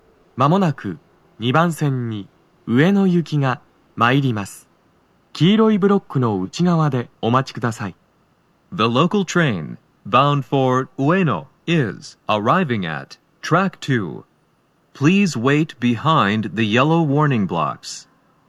スピーカー種類 TOA天井型()
鳴動は、やや遅めです。
2番線 上野・浅草方面 接近放送 【男声